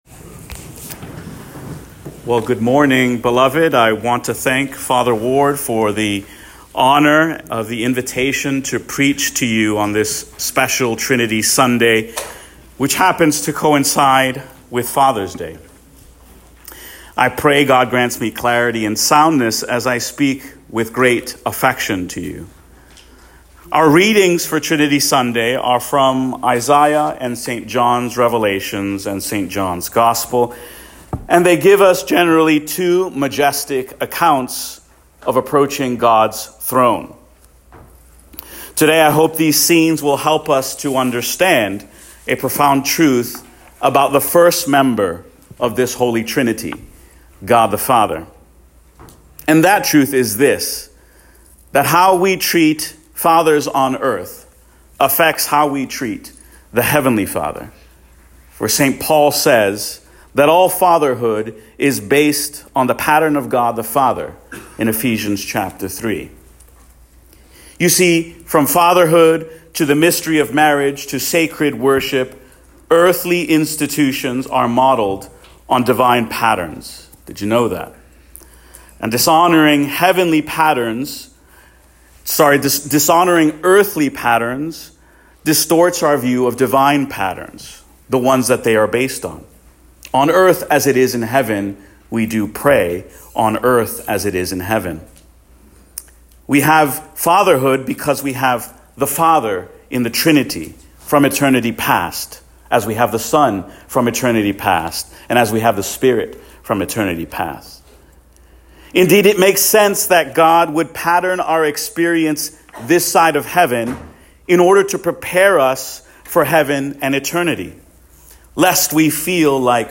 Trinity Sunday & Father’s Day Sermon 2025
Fathers-Day-Sermon.m4a